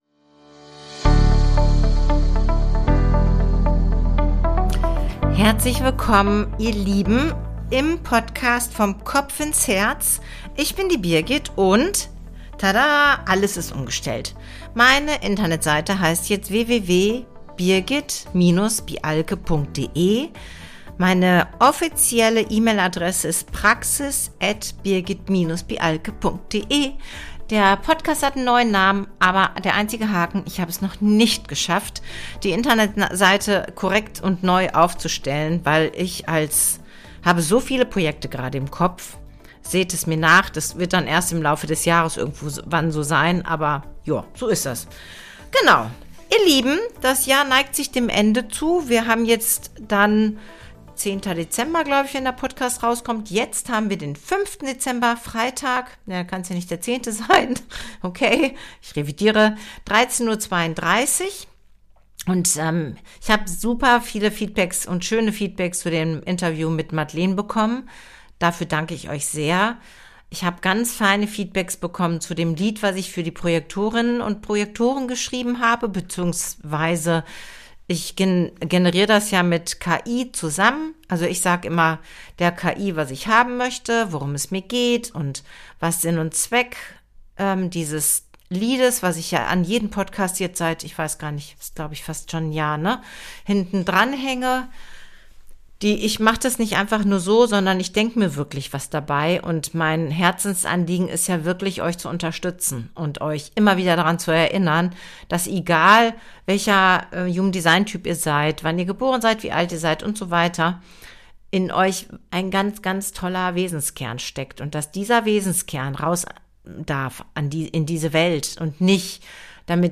Am Ende der Folge wartet ein swingender Song im Roger-Cicero-Stil – eine kleine Hymne für Generatoren und MGs, die deine sakrale Energie feiert.